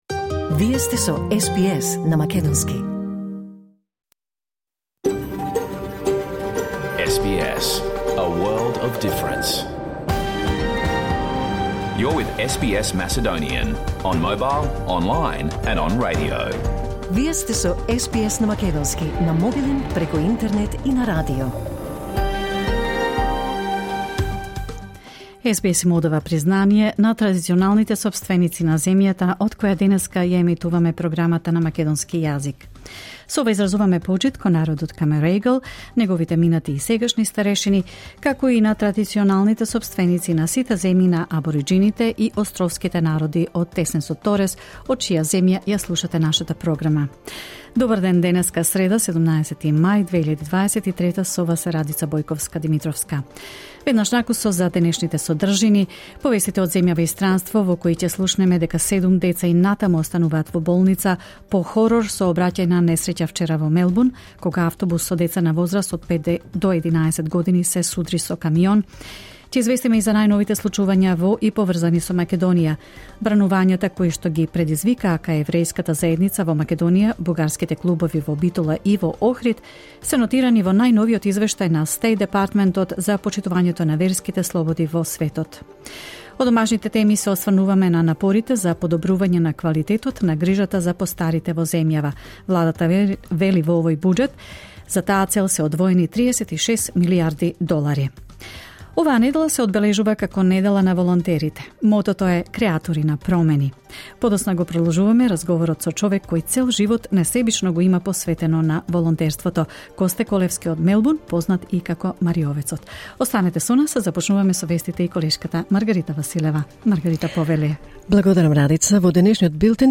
SBS Macedonian Program Live on Air 17 May 2023